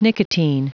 Prononciation du mot nicotine en anglais (fichier audio)
Prononciation du mot : nicotine